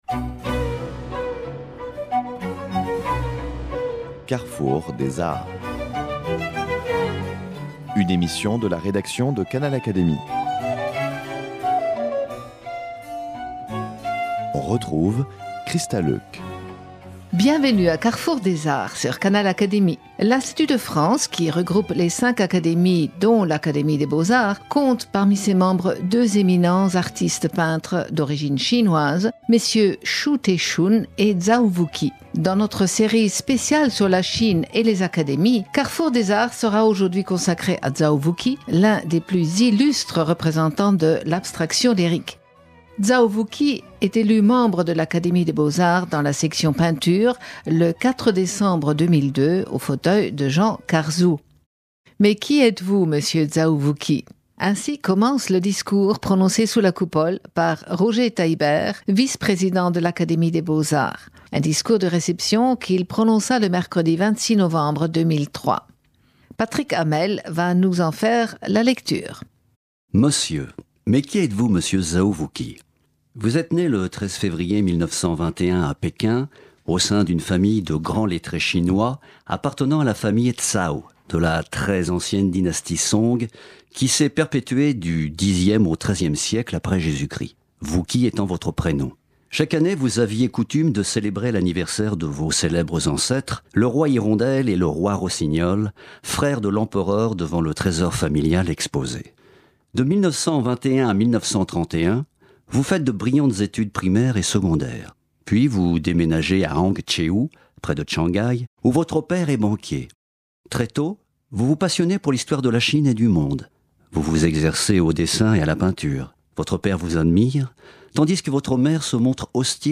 Extraits du discours de réception de Zao Wou-Ki prononcé sous la Coupole le mercredi 26 novembre 2003